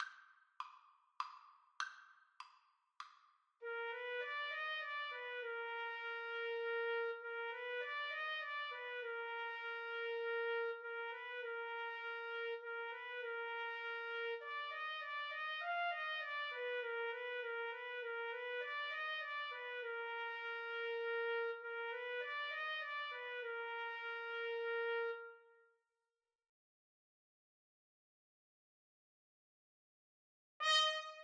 3/4 (View more 3/4 Music)
Trumpet Duet  (View more Easy Trumpet Duet Music)
Classical (View more Classical Trumpet Duet Music)